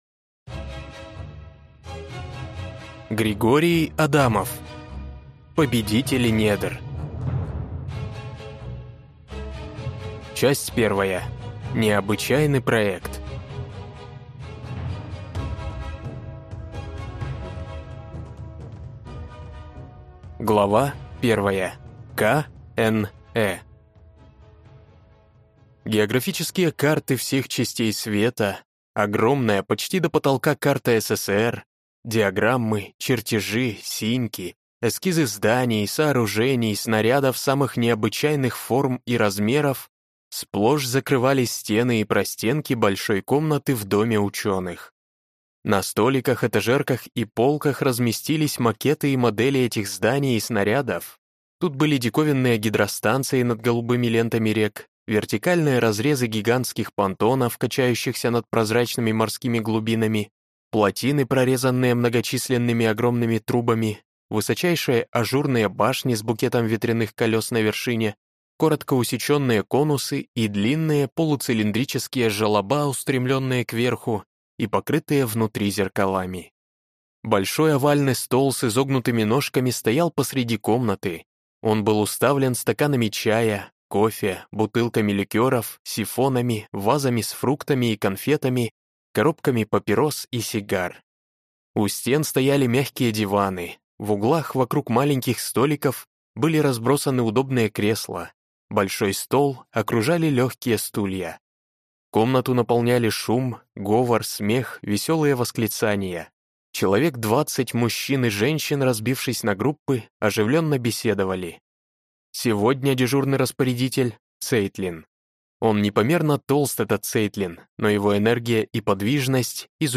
Аудиокнига Победители недр | Библиотека аудиокниг